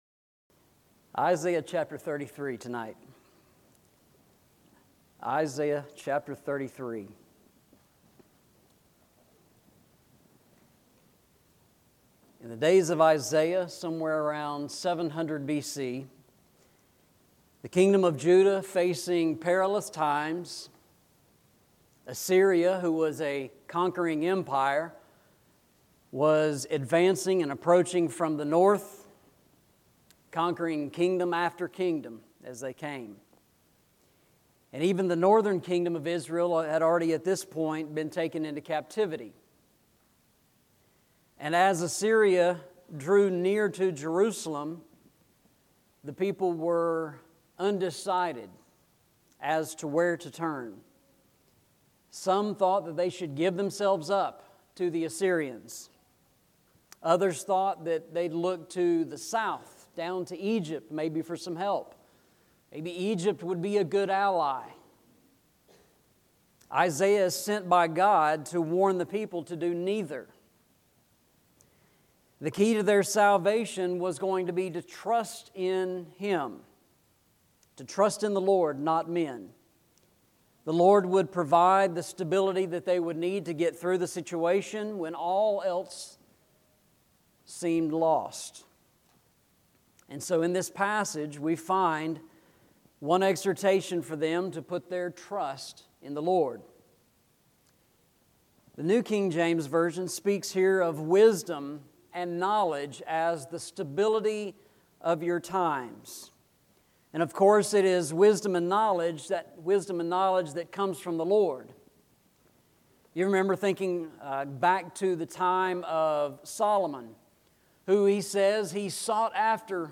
Eastside Sermons Passage: Isaiah 33:5-6 Service Type: Sunday Evening « Called